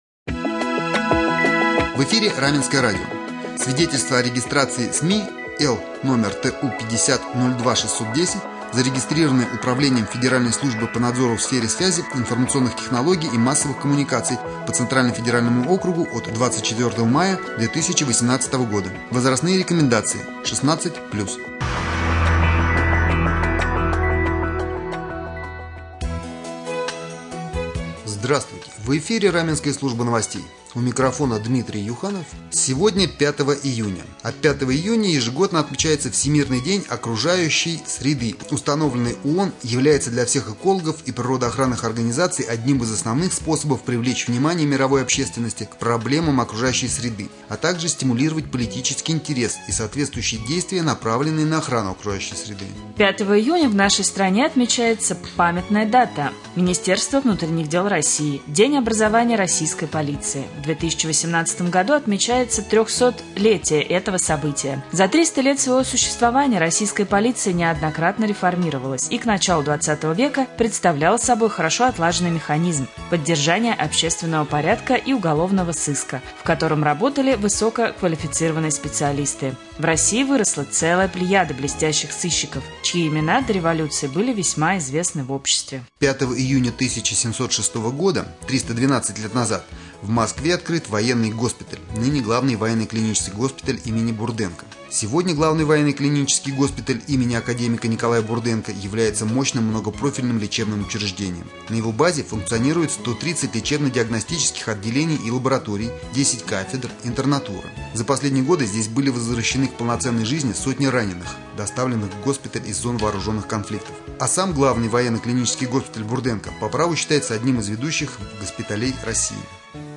1.Novosti-1.mp3